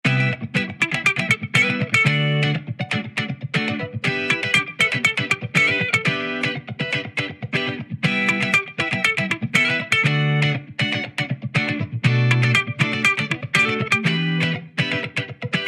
KIT2_90_Guitar_Soul_Chords_D#min